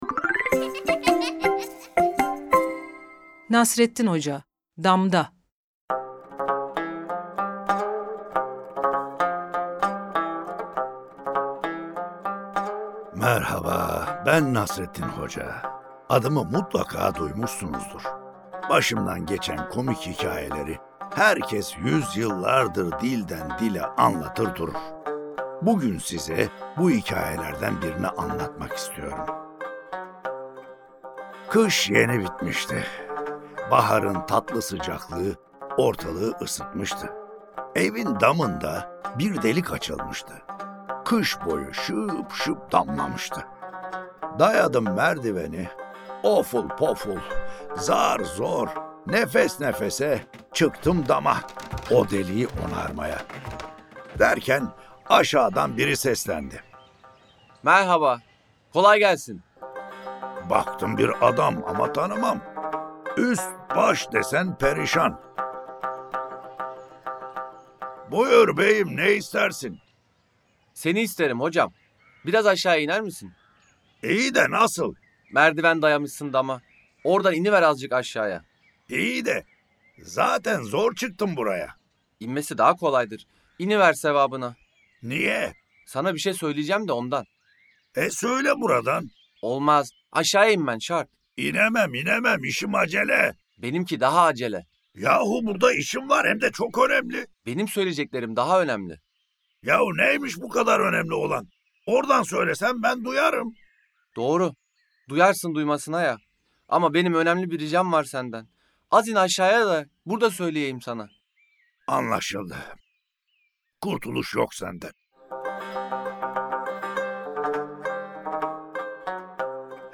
Nasreddin Hoca: Damda Tiyatrosu